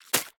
Sfx_creature_babypenguin_hop_02.ogg